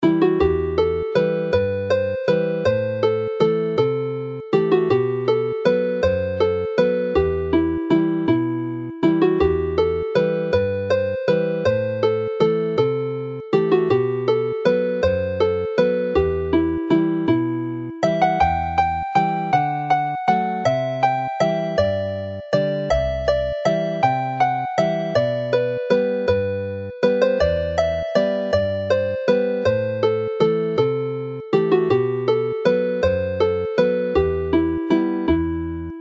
Chwarae'r alaw'n araf
Play the tune slowly